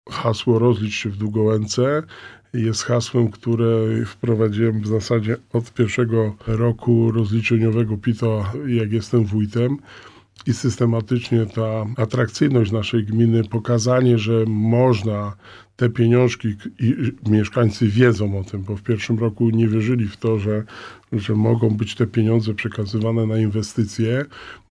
– To bardzo ważne dla każdego wójta, burmistrza oraz mieszkańca – mówi Wojciech Błoński.